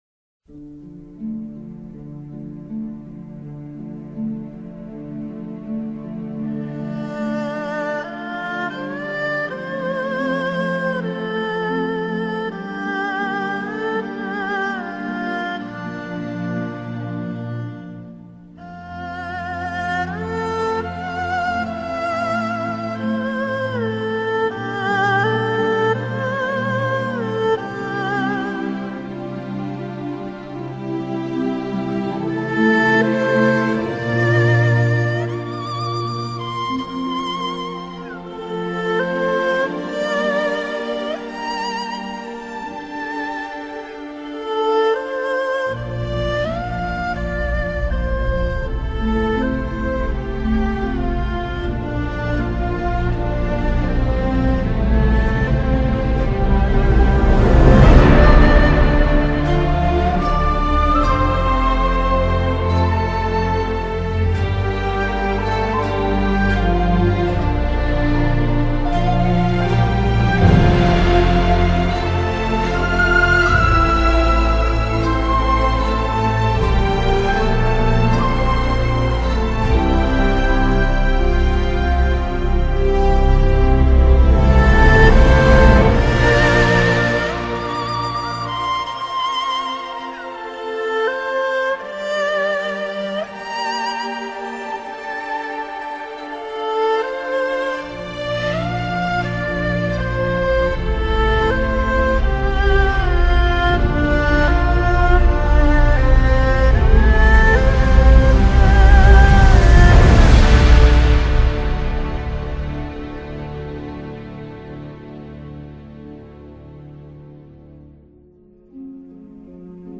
מדהים ביותר -- כמה דברים ממש אהבתי הנגינה מאוד מדויקת ומתוזמנת מעולה וגם מתפתחת כל הזמן ומבינים שאתה מבין במוסיקה ולא רק יודע ללחוץ על כפתורים ממש משמח שיש יוצרים כמוך בפורום וסתם ככה שאלה המנגינה מאוד מוכרת -- מה הכוונה יצרת ?